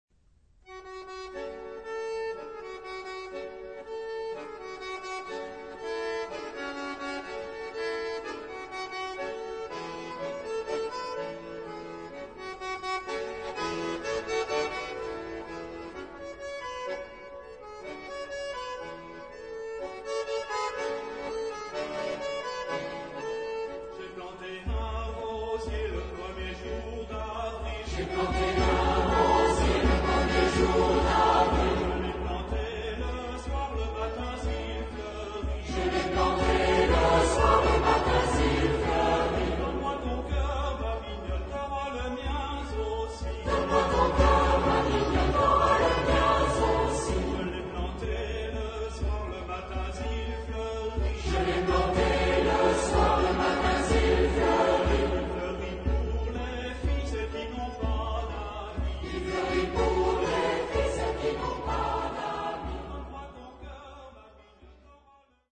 Genre-Style-Form: Popular ; Traditional ; Partsong ; Dance ; Secular ; Song with repetition
Mood of the piece: joyous
Type of Choir: SATB  (4 mixed voices )
Soloist(s): 1 au choix  (1 soloist(s))
Tonality: D major